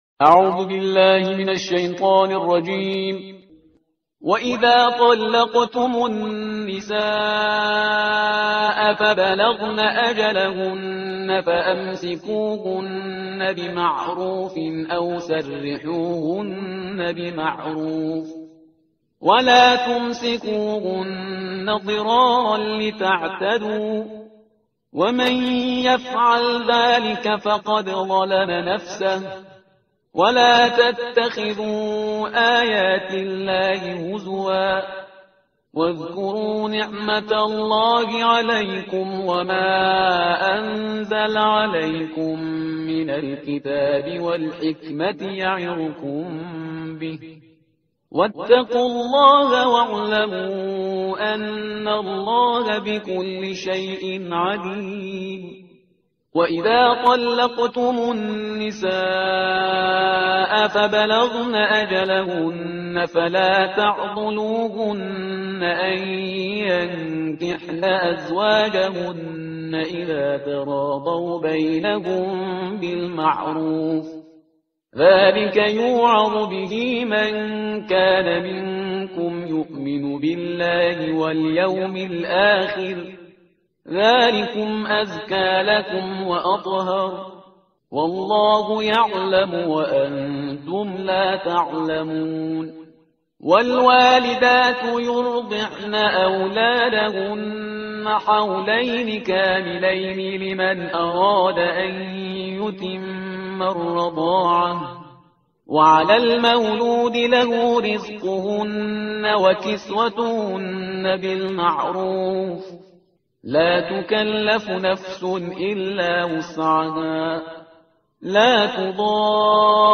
ترتیل صفحه 37 قرآن با صدای شهریار پرهیزگار